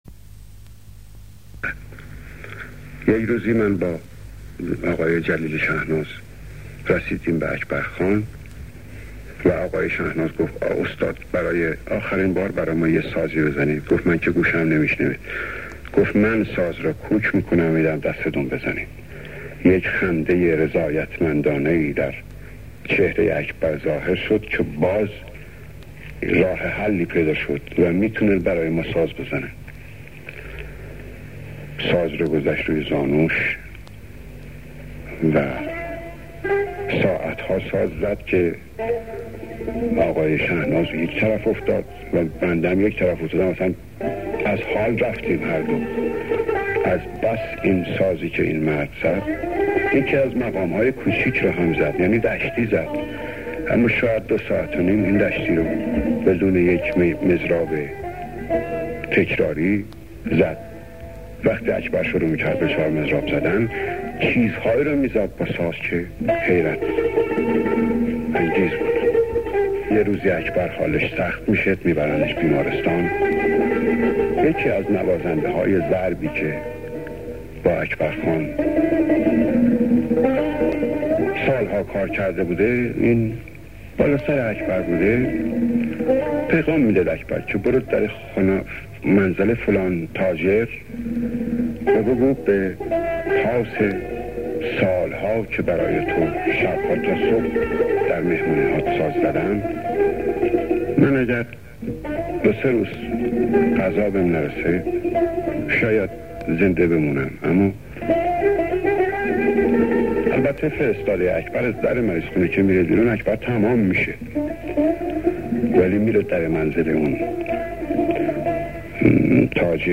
بخشی از روایت زندگانی اکبرخان نوروزی به روایت استاد حسن کسائی